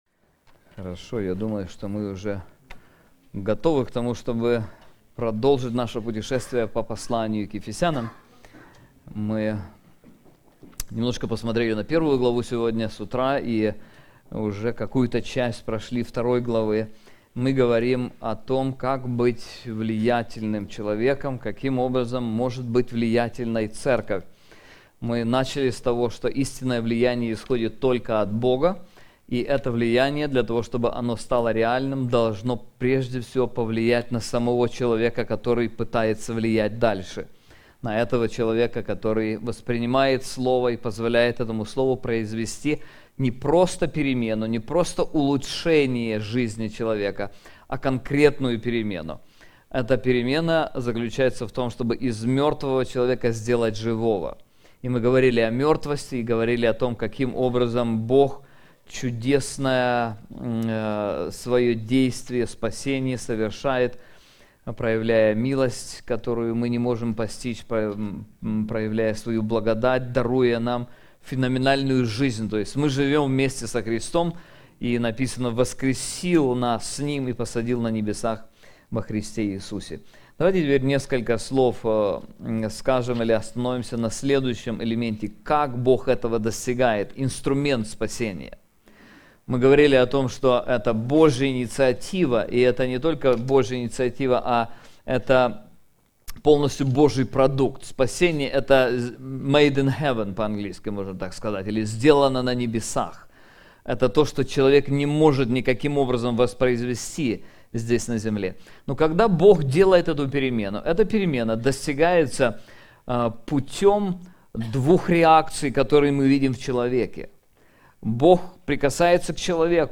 Конференции Влияние Церкви Христовой